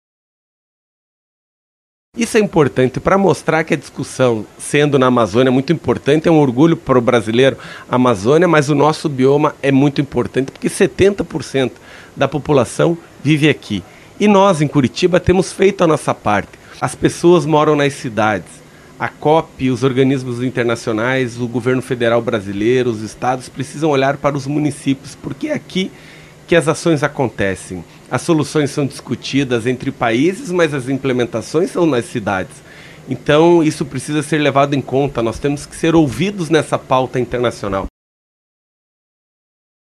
À frente da cidade anfitriã, o prefeito Eduardo Pimentel destacou a expectativa por soluções que olhem de perto para as cidades.